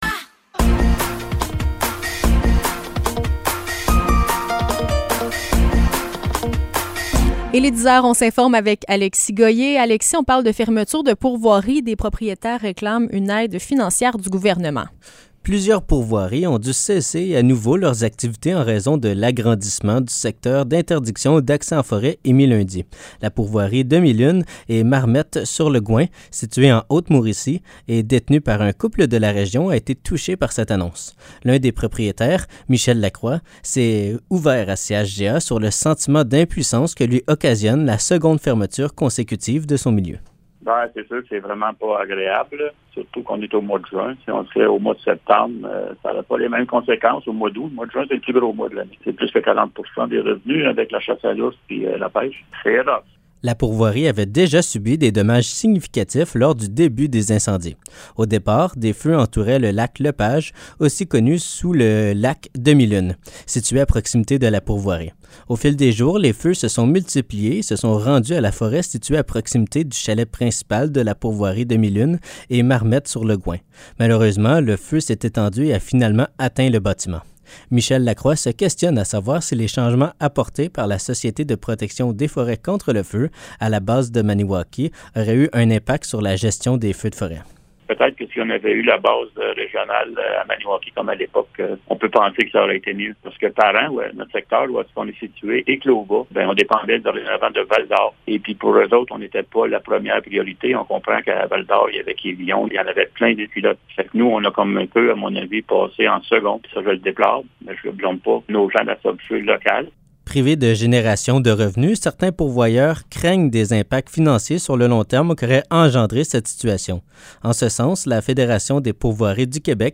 Nouvelles locales - 22 juin 2023 - 10 h